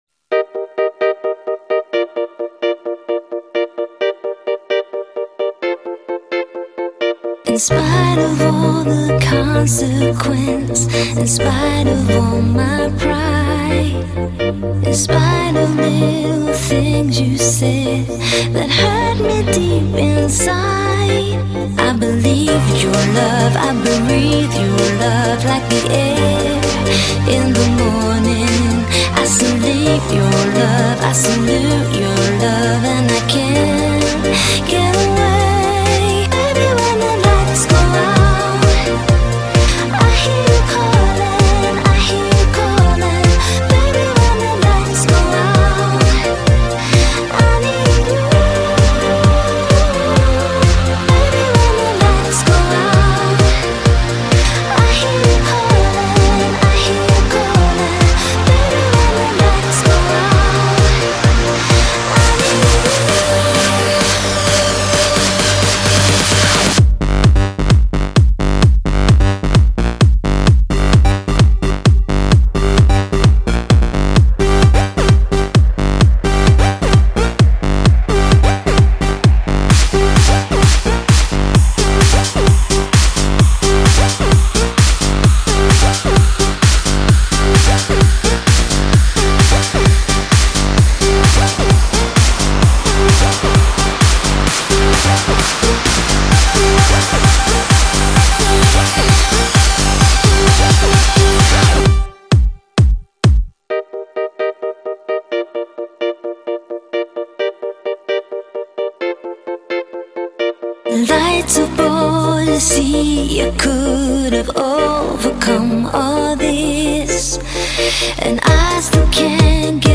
*** (стиль electro house) ***